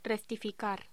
Locución: Rectificar
voz
Sonidos: Voz humana